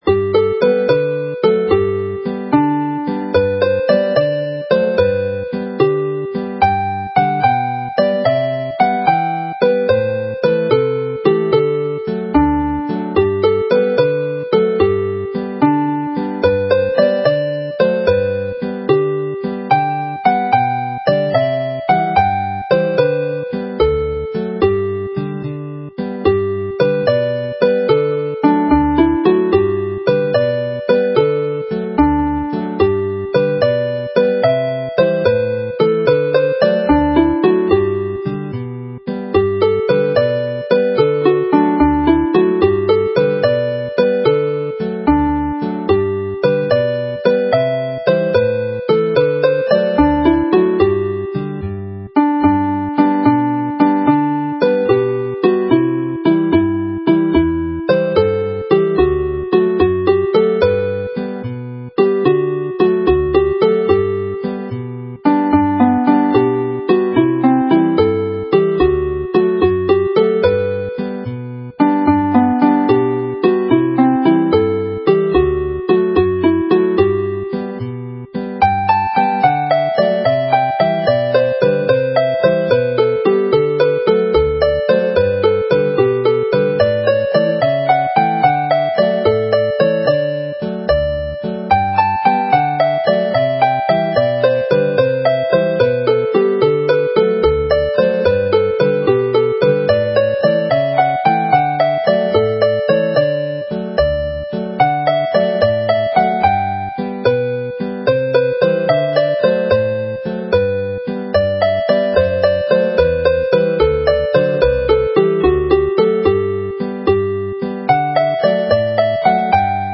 Play the set slowly
This set of jolly jigs